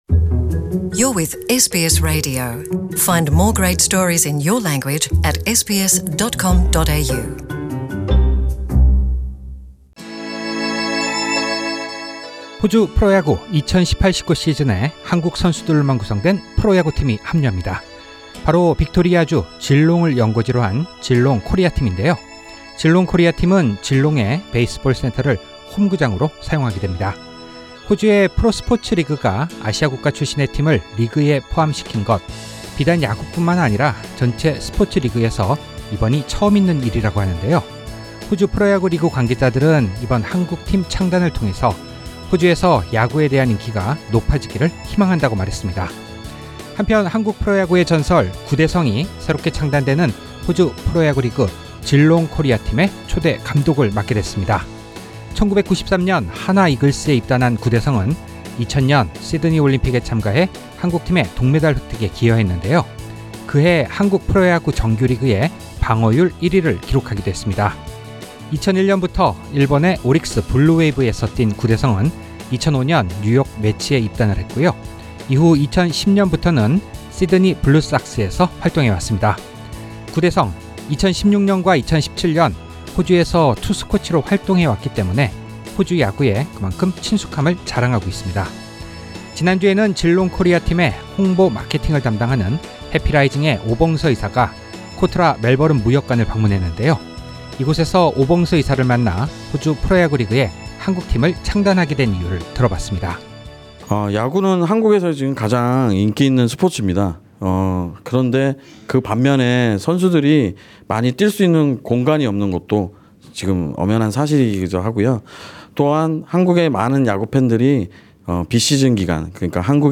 상단의 오디오 다시듣기(팟캐스트)를 클릭하시면 인터뷰 전체 내용을 들을 수 있습니다.